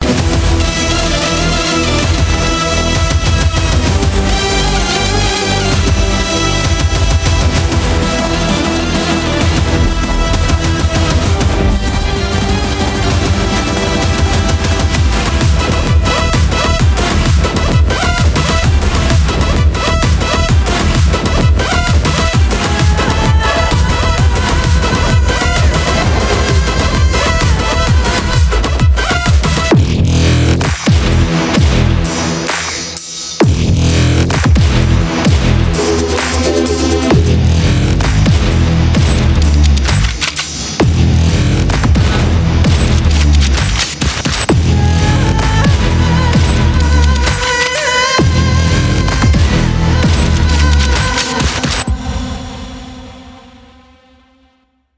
without any dialogues and disturbances